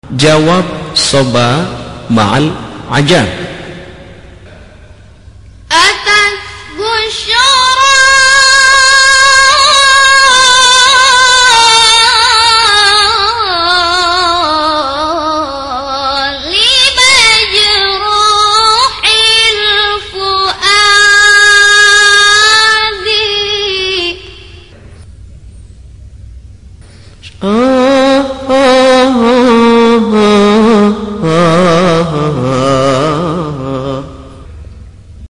صبا جواب ترکیب با عجم 3.mp3